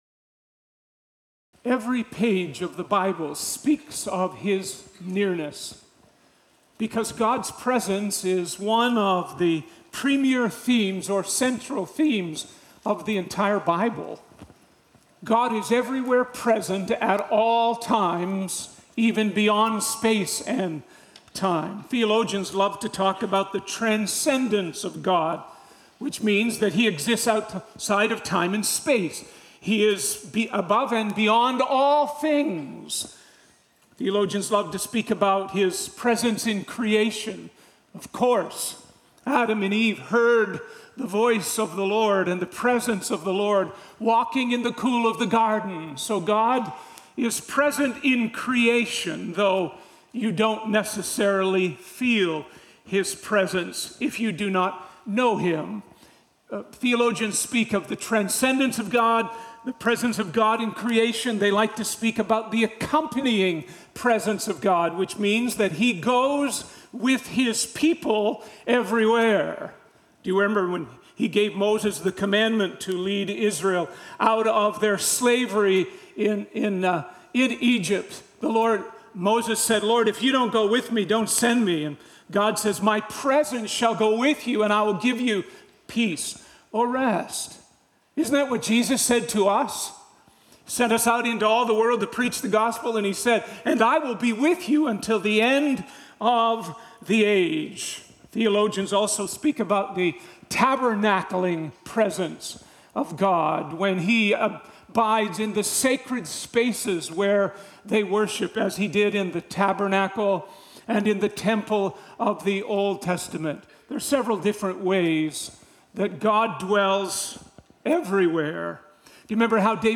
In this encouraging sermon, we are reminded that no matter the trial, God’s presence brings peace, purpose, and hope.